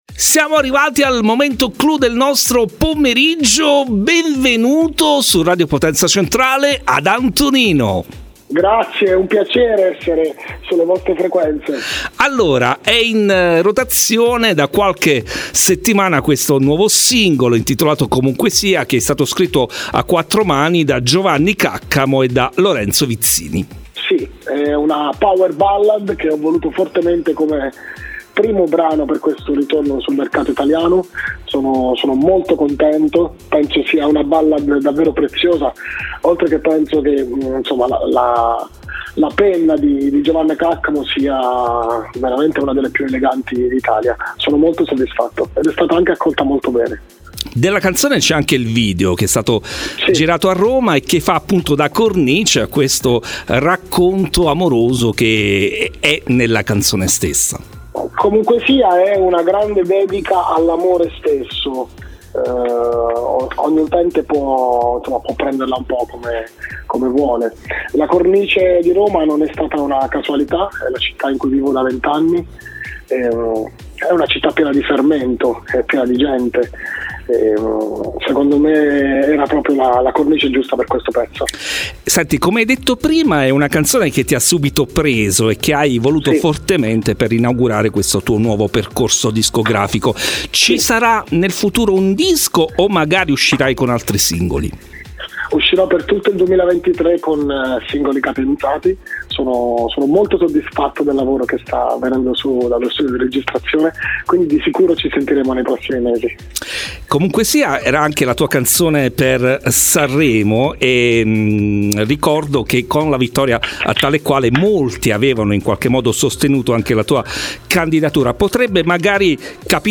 INTERVISTA-ANTONINO-FEBBRAIO-2023.mp3